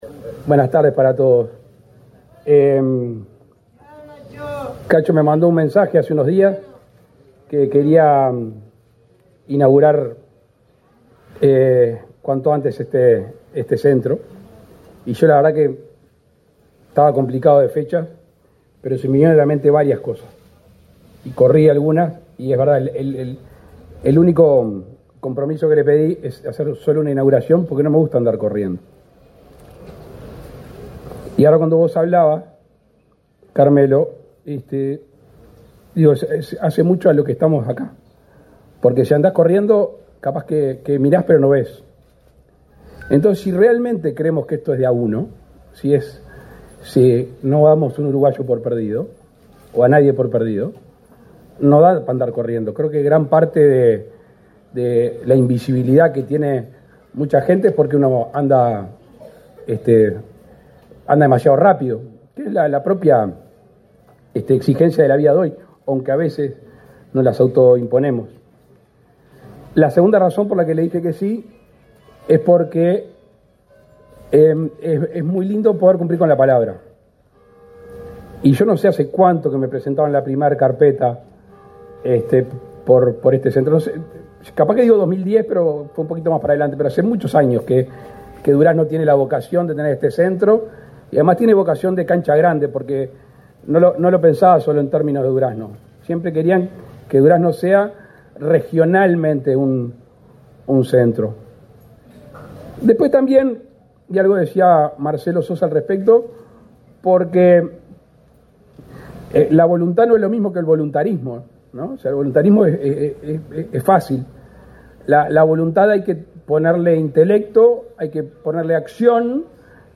Palabras del presidente de la República, Luis Lacalle Pou
El presidente de la República, Luis Lacalle Pou, participó, este 22 de noviembre, en la inauguración del Centro Residencial Durazno (Red Nacional de